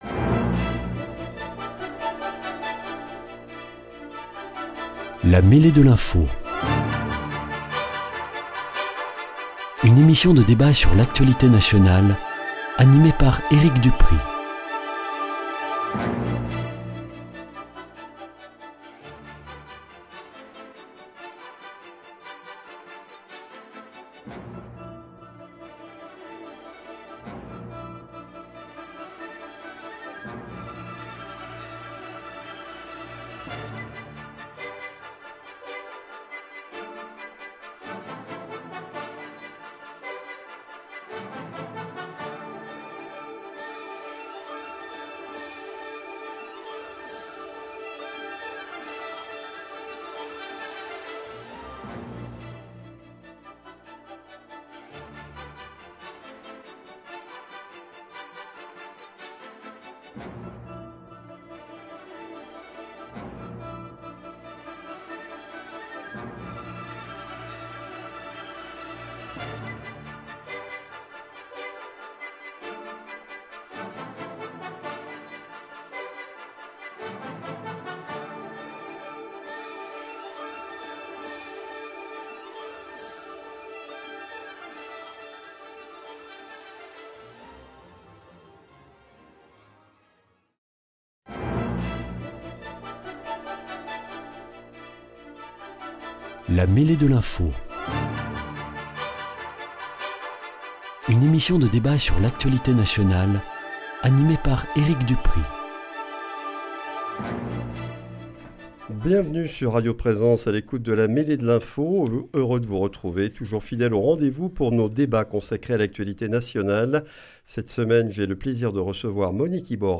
Sujets : E.Macron reconnaît l'état de Palestine / Drapeau palestinien au fronton des mairies Invités : Monique IBORRA, ex-députée de la Haute-Garonne, membre du parti Renaissance